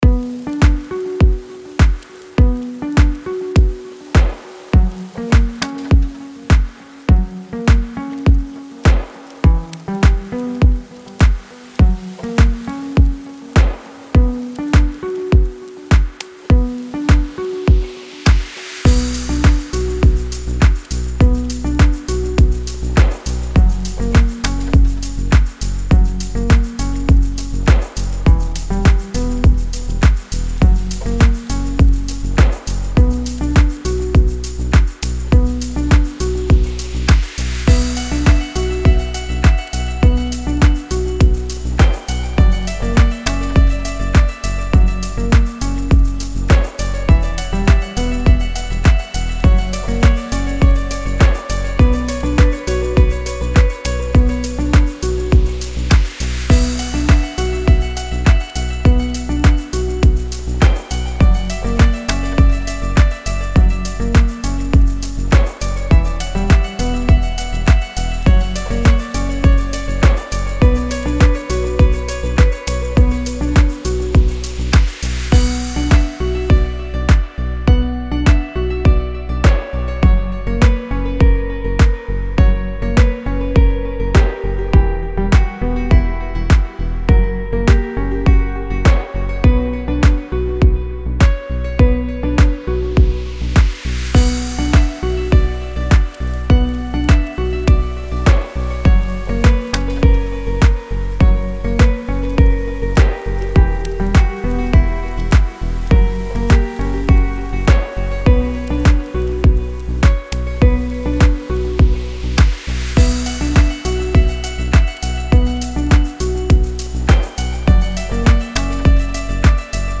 دیپ هاوس ریتمیک آرام موسیقی بی کلام